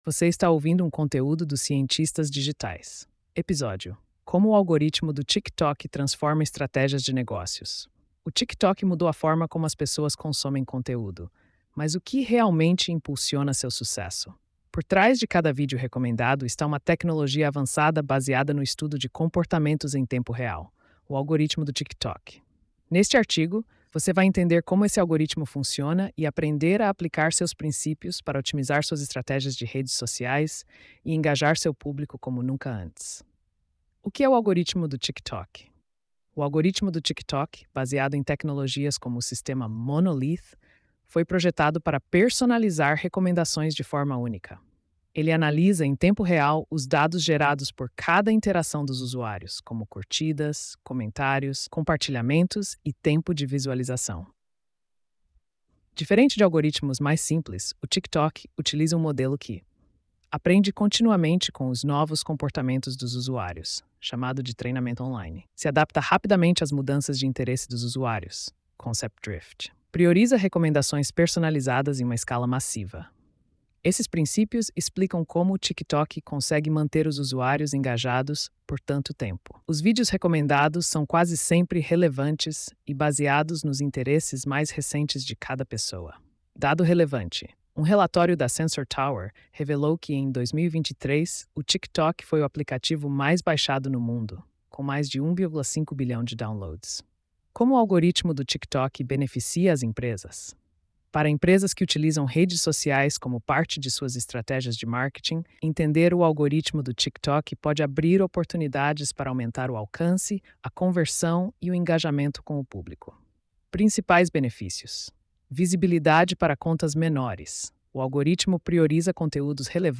post-2687-tts.mp3